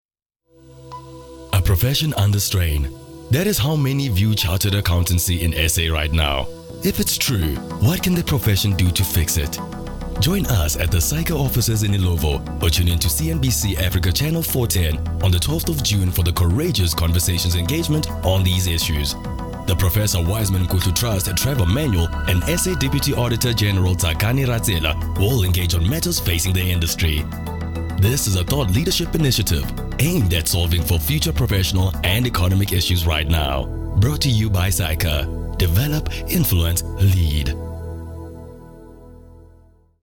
South Africa
agile, brisk, fast-paced, rapid
My demo reels